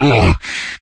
controller_hit_1.ogg